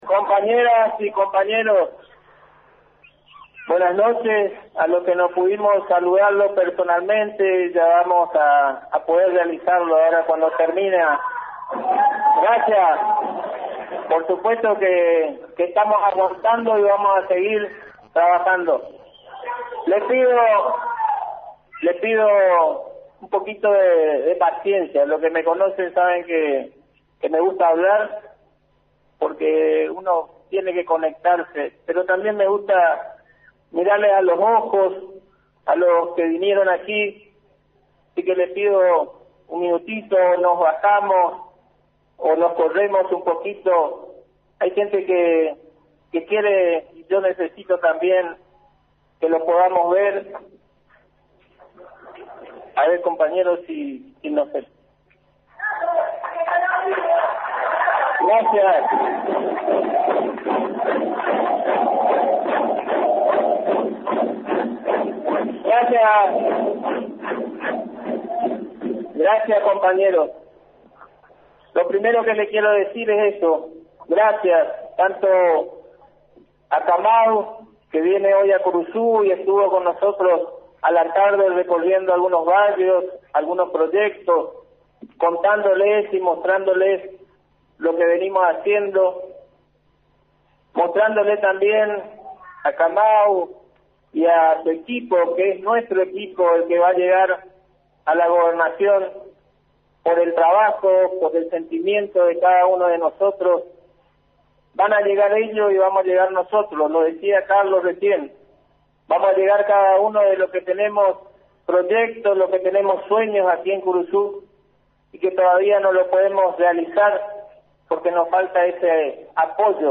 Intendente Domínguez: Discurso completo
lalo_discurso_lanzamiento.mp3